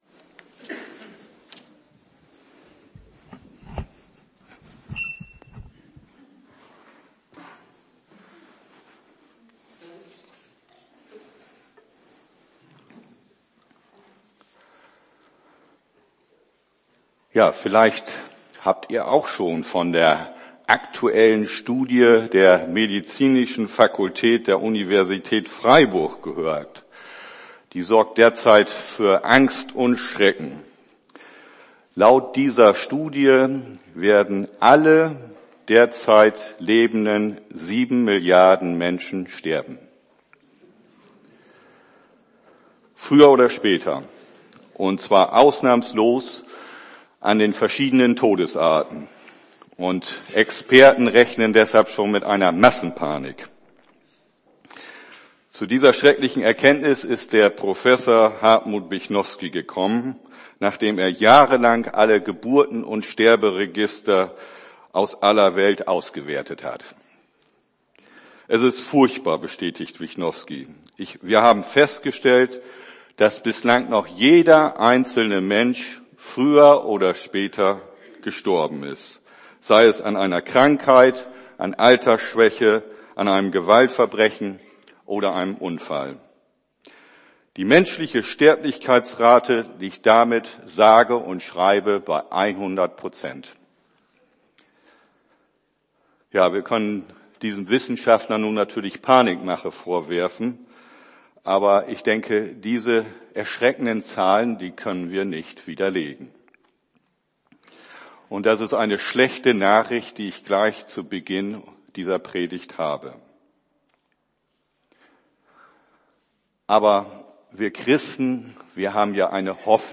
Predigt vom 30.Juni 2013 Predigt Predigttext: Kol 3,1+2 Joh 5,24 Offb 22,2 Jes 66,23 Jes 65,17-25 Math 17,1-4; 8,11